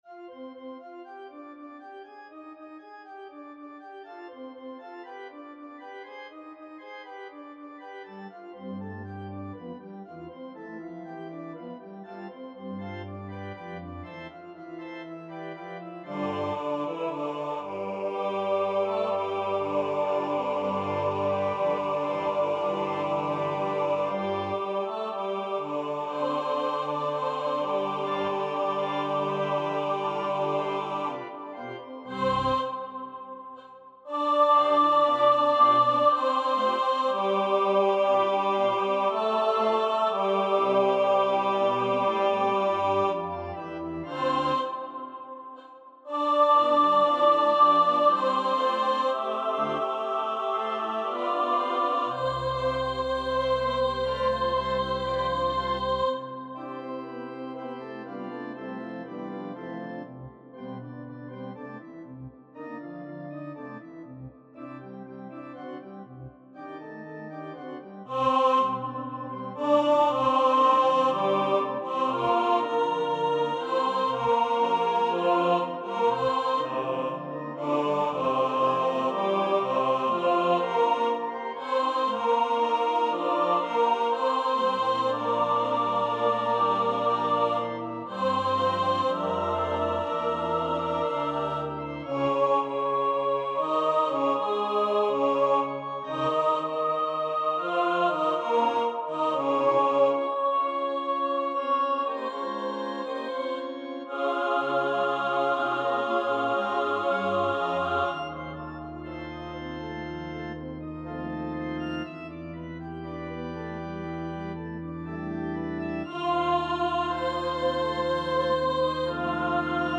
• Music Type: Choral
• Voicing: SATB
• Accompaniment: Organ